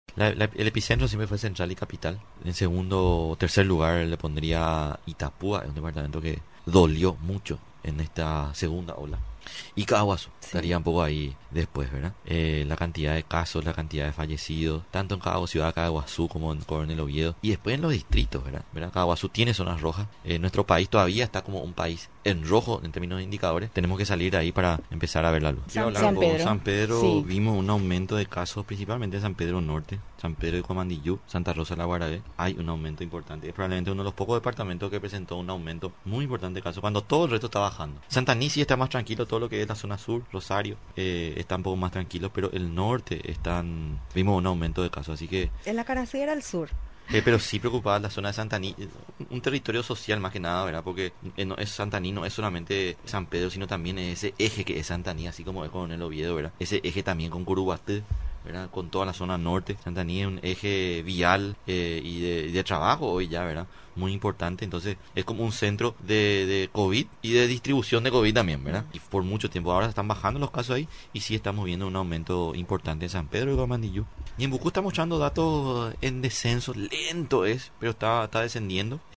Foto: Telám El Director General de Vigilancia de Salud, Guillermo Sequera, habló con Radio Nacional de Paraguay sobre la actualidad sanitaria del país en cuanto al Covid-19. El profesional destacó el descenso de casos y masiva concurrencia a vacunación, que cerró con más de 50.000 inmunizados en esta etapa para personas con patologías de base.